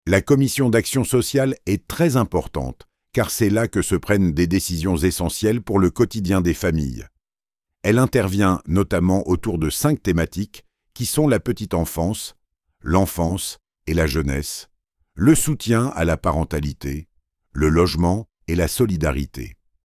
Voici 2 enregistrements : l’un a été généré par une Intelligence Artificielle de pointe, l’autre a été interprété par un comédien professionnel.
Écoutez attentivement les micro-inflexions, le souffle et l’intention…